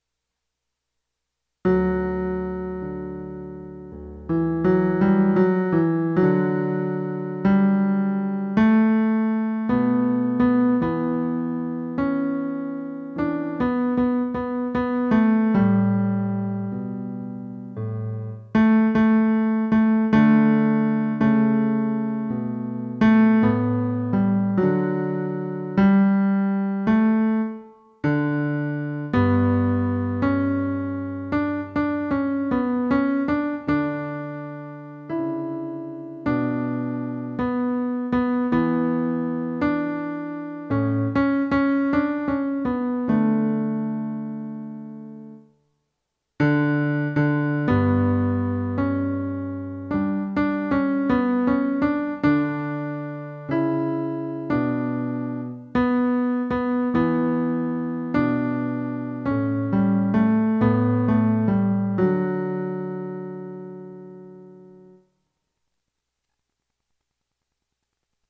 Lord, You Have Come - Tenor